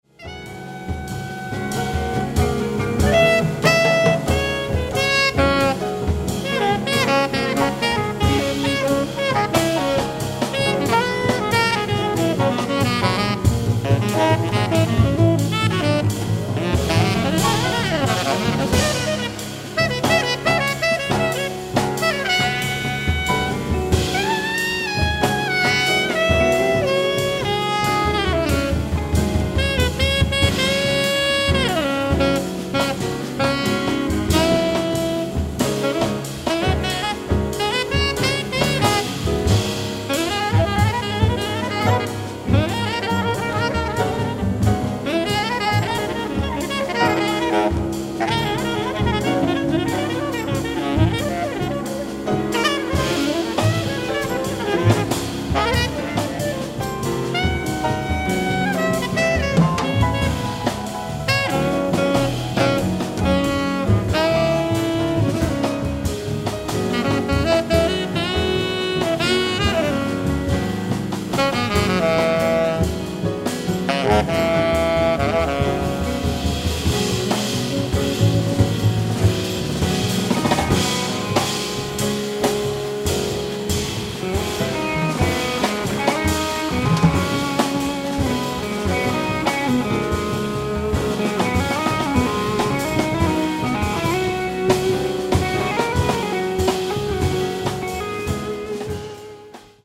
ライブ・アット・ローザンヌ、スイス
※試聴用に実際より音質を落としています。